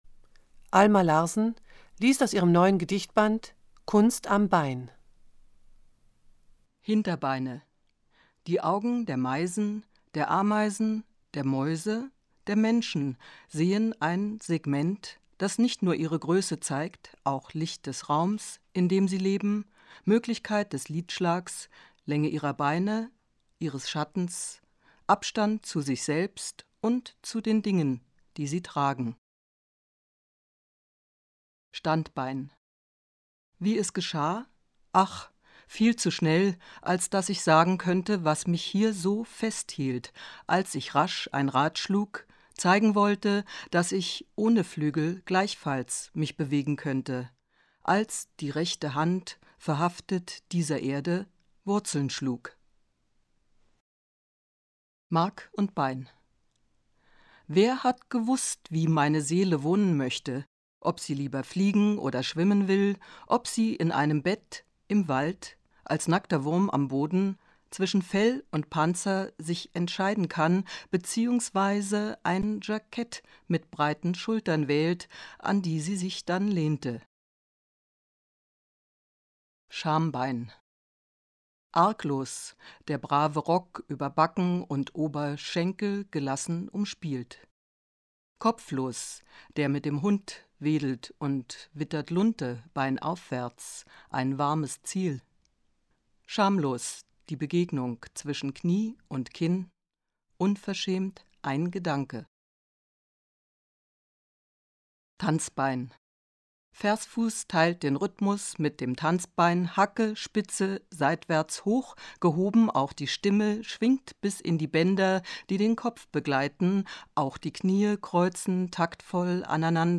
Das Literaturtelefon-Archiv wird in der Monacensia im Hildebrandhaus aufbewahrt. Es umfasst 40 CDs, auf denen insgesamt 573 Lesungen enthalten sind.